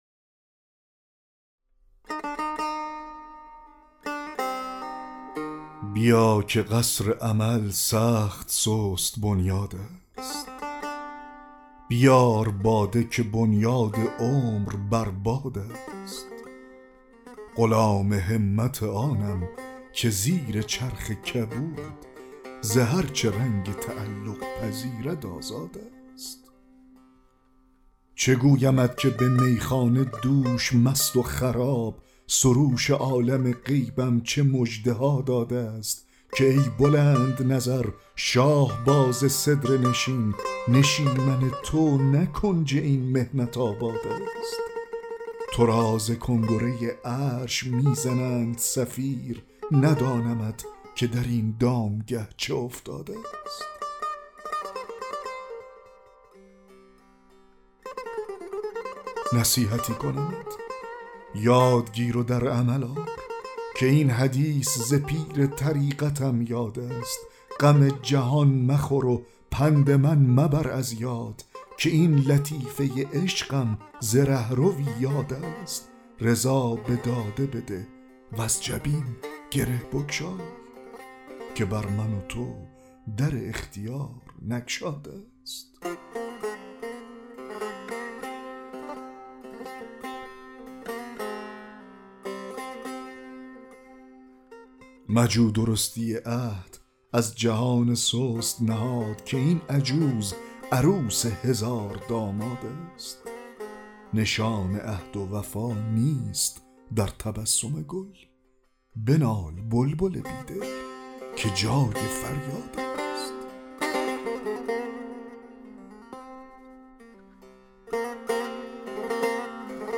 دکلمه غزل 37 حافظ